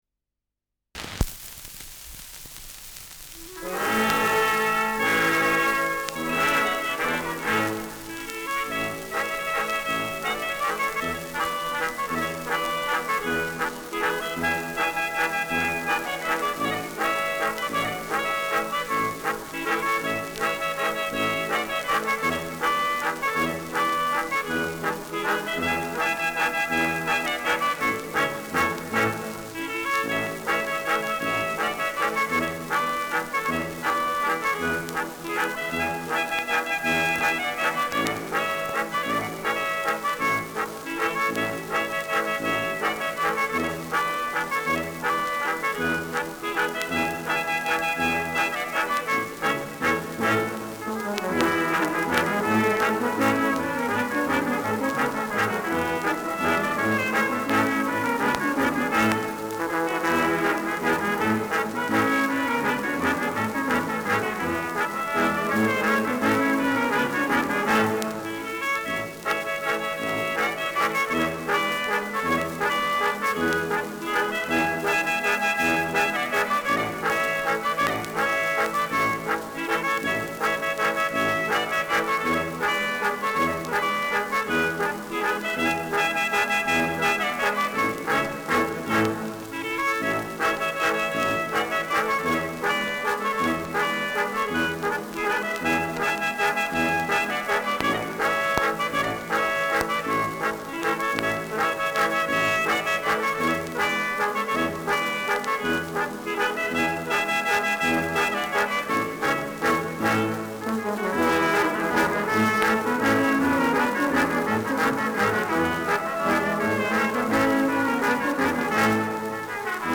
Schellackplatte
Tonrille: Kratzer 2 Uhr Stärker
präsentes Rauschen
Kapelle Jais (Interpretation)
[München] (Aufnahmeort)